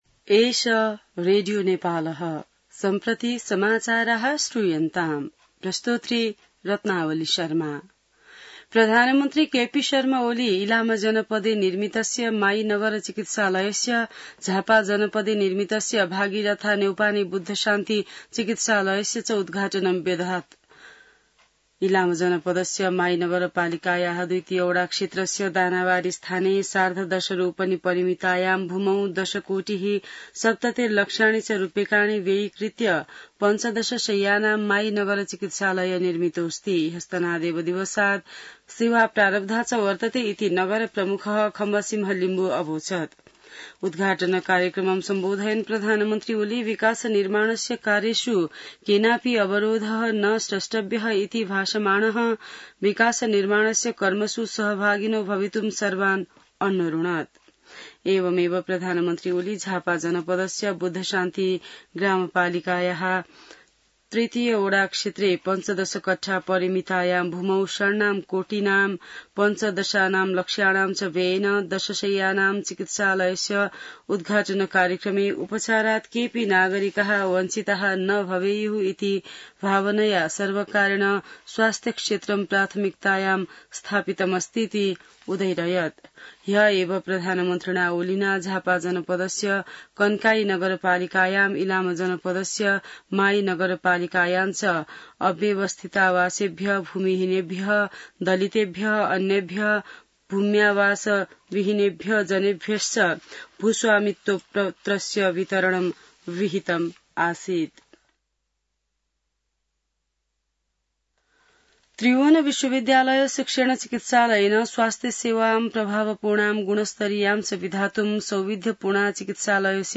संस्कृत समाचार : २९ असार , २०८२